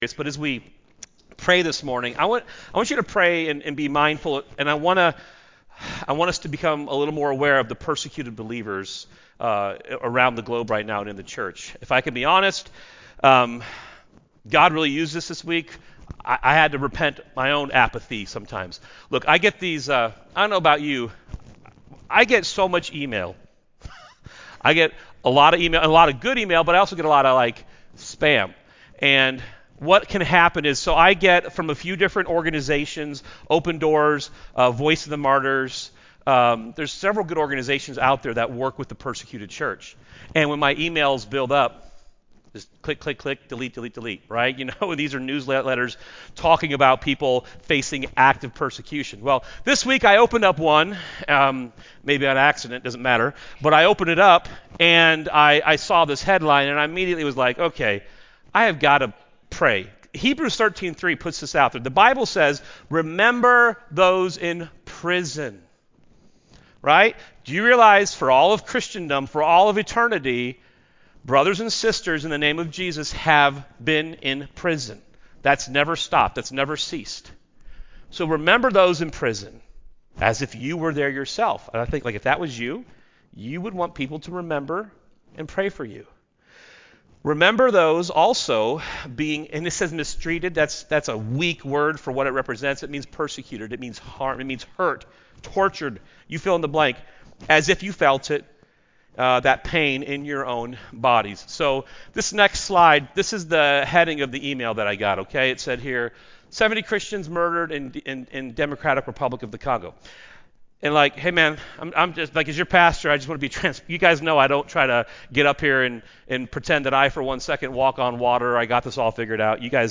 A message from the series "ACTS."